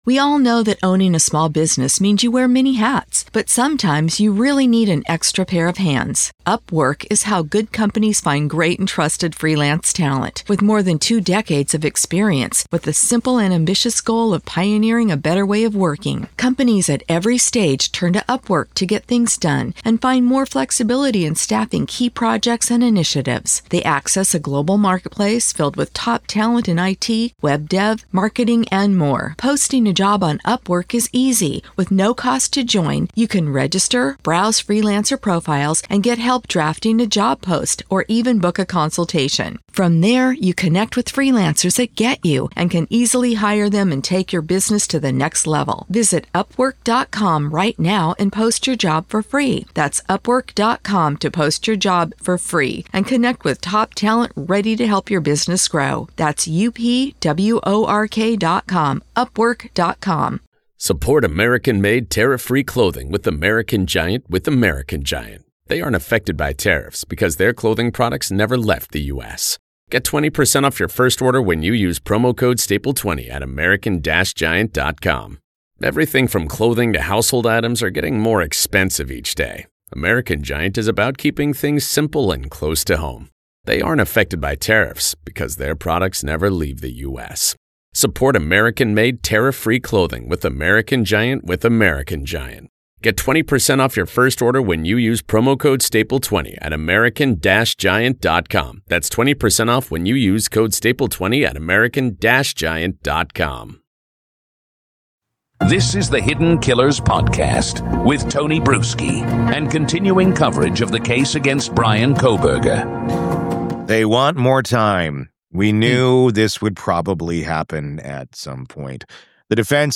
This is the interview that goes beyond the headlines and into the evidence-based behavioral framework that law enforcement uses every day.